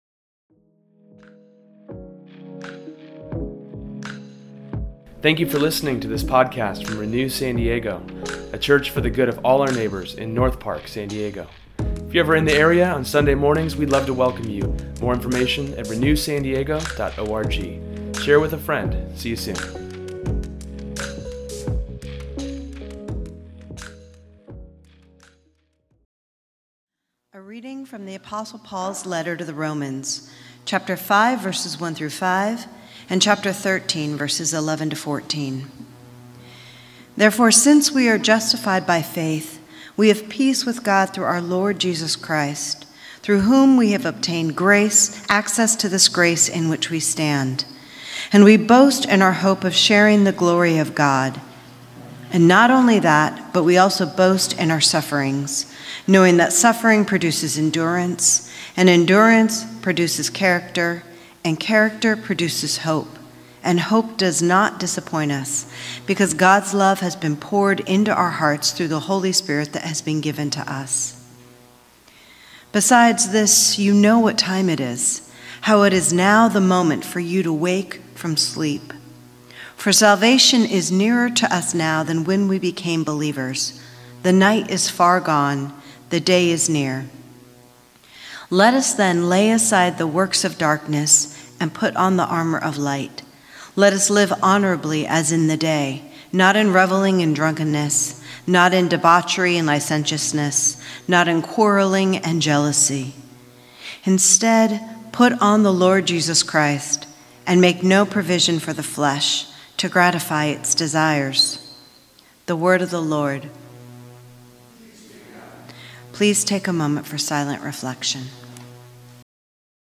Today’s sermon is on the start of advent, and explores living this life at the moment, but with a mindful expectation of Jesus’s imminent return.